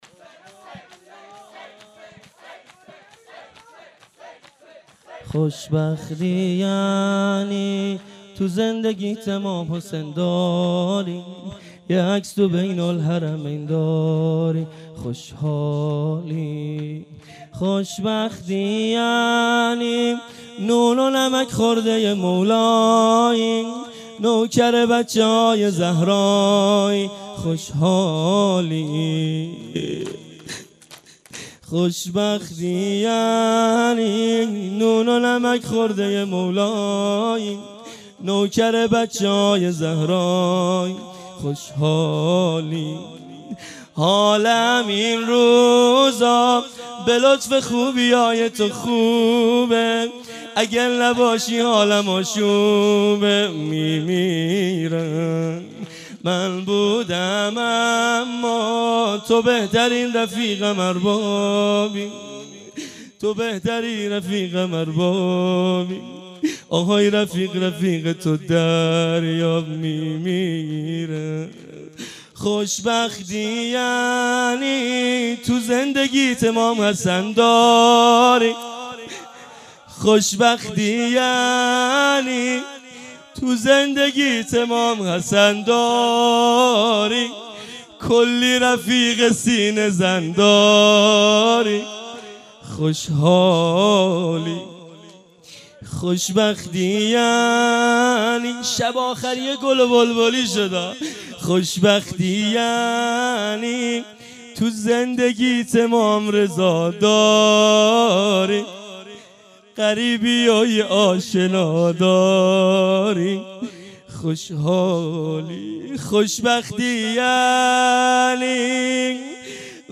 فاطمیه97- مجمع دلسوختگان بقیع- شب پنجم- شور و روضه پایانی